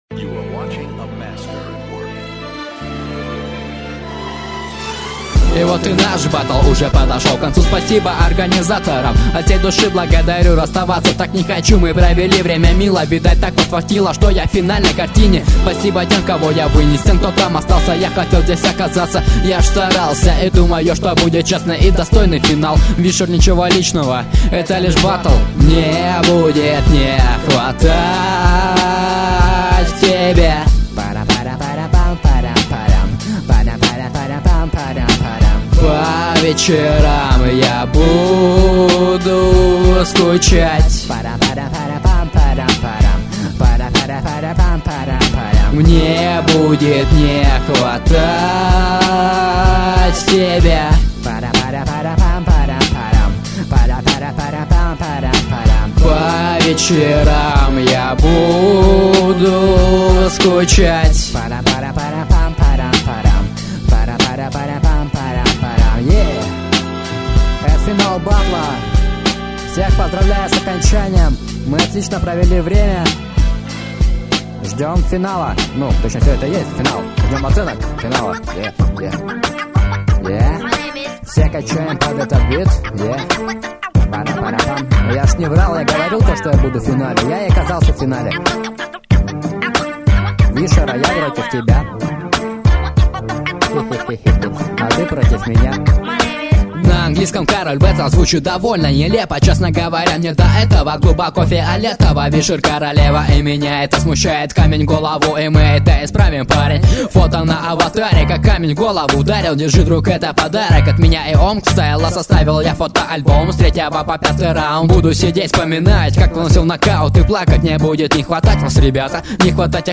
• Баттлы:, 2006-07 Хип-хоп
mp3,4429k] Рэп